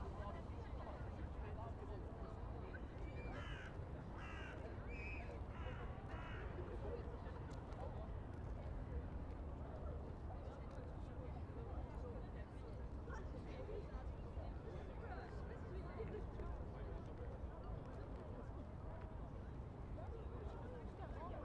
In the early stage, we captured the ambience by recording both point sources and area sound sources. We went to Calton Hill and used the Zoom H6 recorder to record the ambience in different directions of Calton Hill.